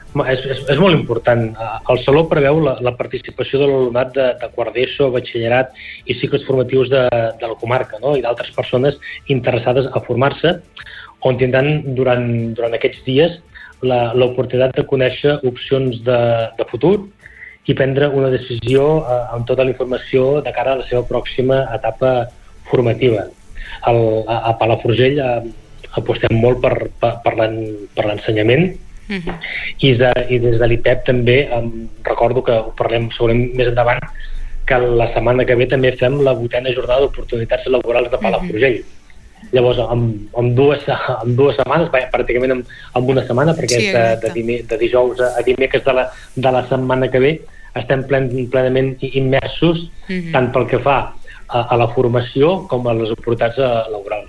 Supermatí - entrevistes
Per parlar-nos d’aquest acte i altres propostes que arribaran al municipi ens ha visitat al Supermatí el regidor de promoció econòmica de l’Ajuntament de Palafrugell, Marc Piferrer.